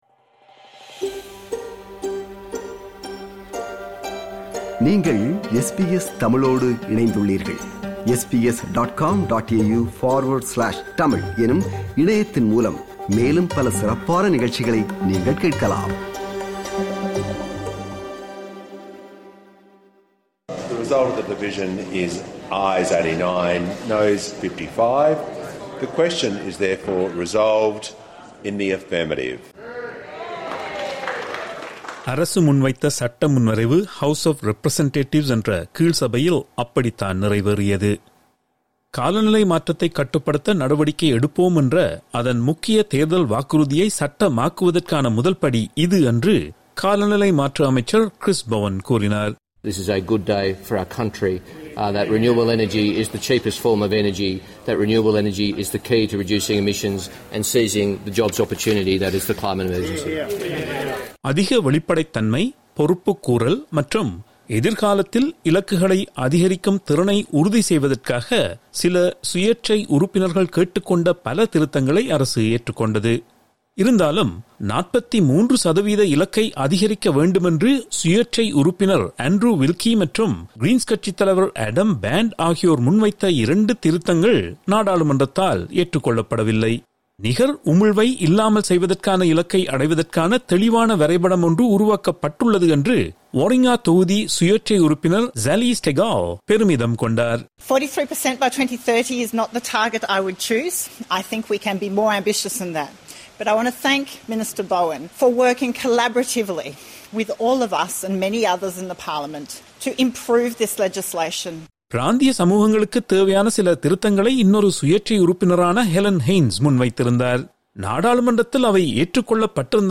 reports in Tamil